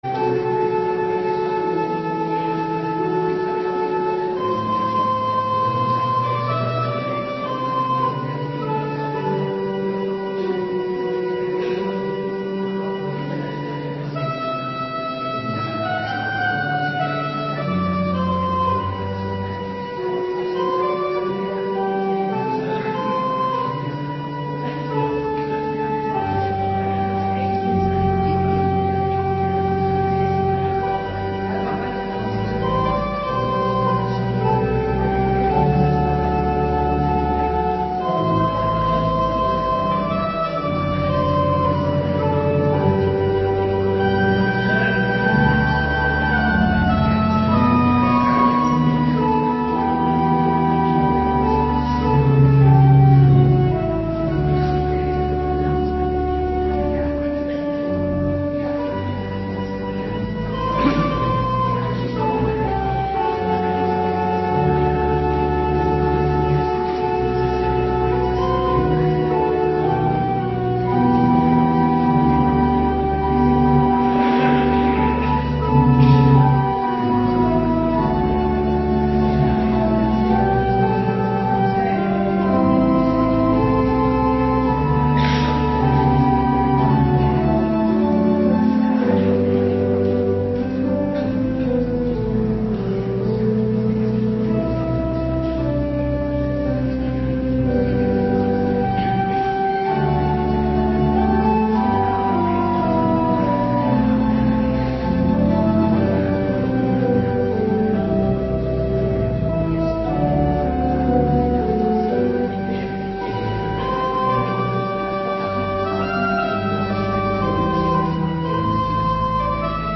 Morgendienst 1 januari 2026